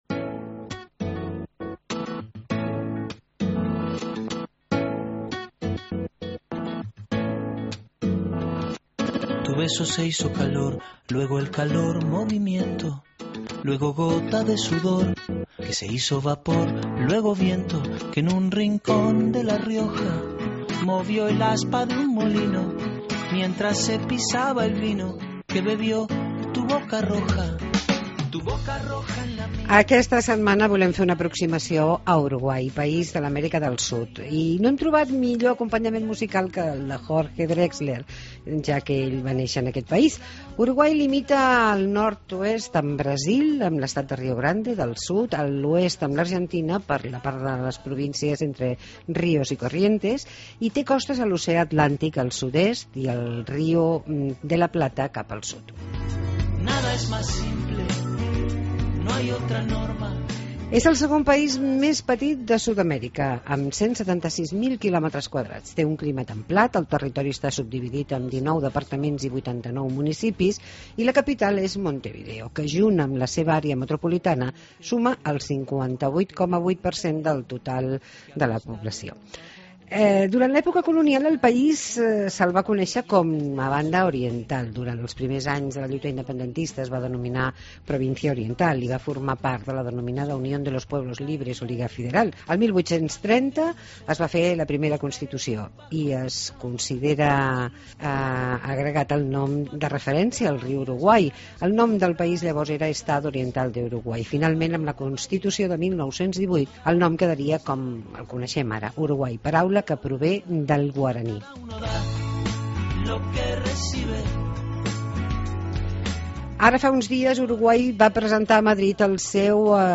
Entrevista a Benjamín Liberoff, director nacional de Turismo de Uruguay